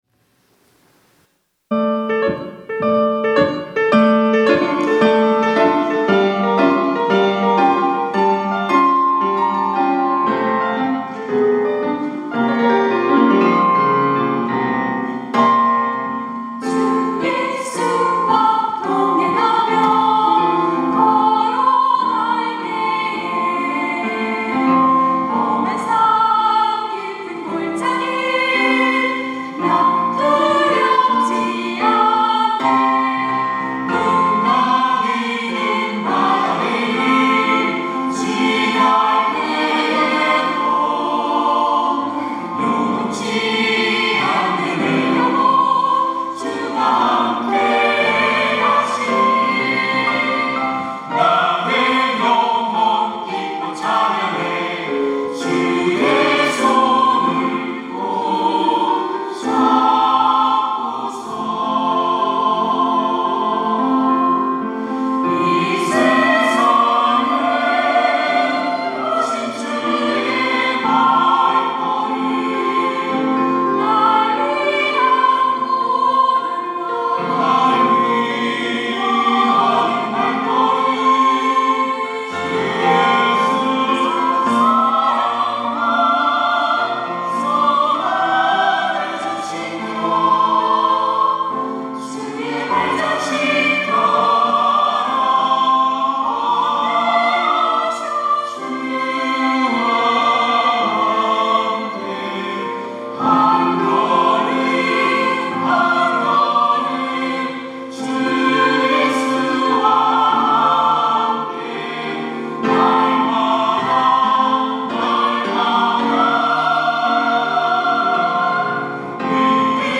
특송과 특주 - 한걸음 한걸음